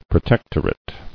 [pro·tec·tor·ate]